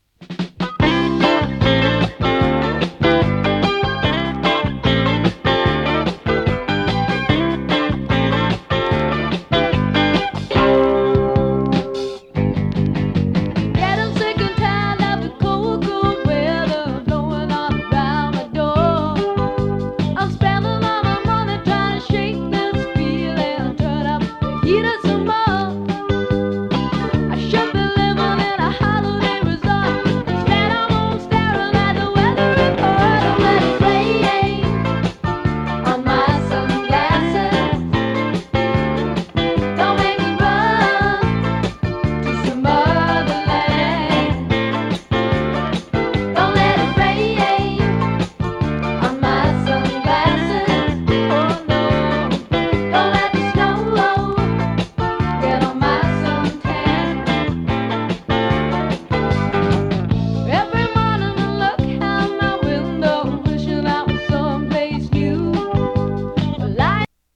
豪州ジャイヴ ネオロカ